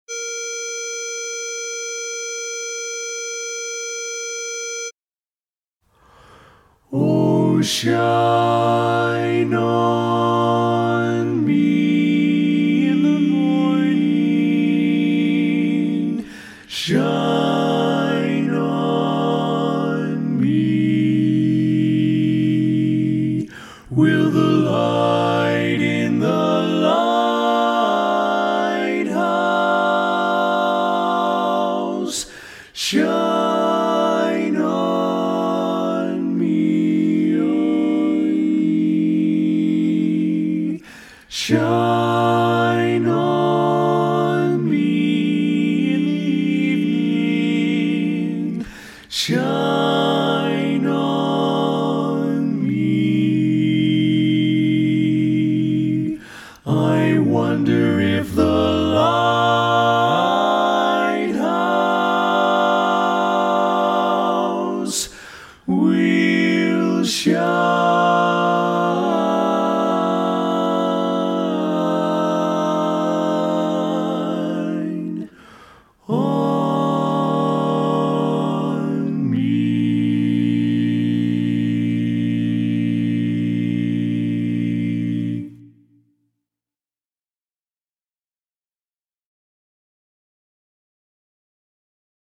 Barbershop
Tenor